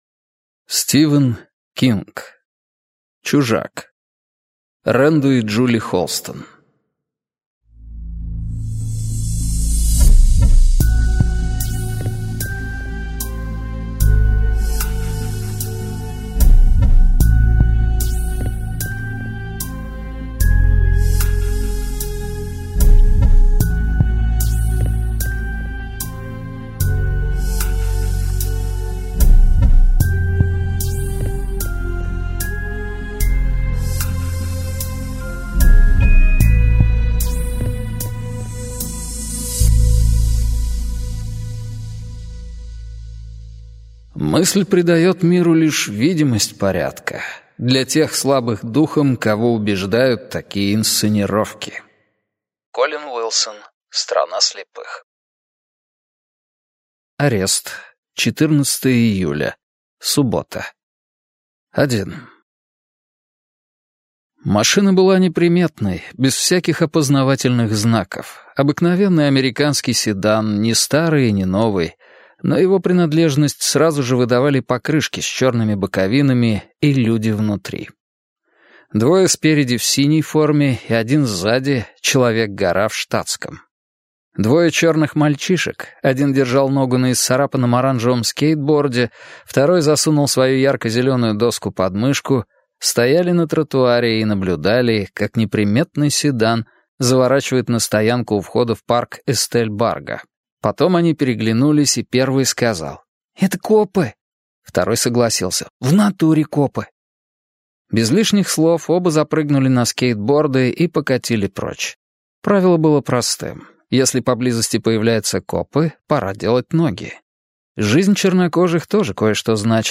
Аудиокнига Чужак - купить, скачать и слушать онлайн | КнигоПоиск